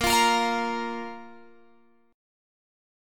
A#5 chord {18 20 20 x 18 18} chord